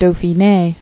[Dau·phi·né]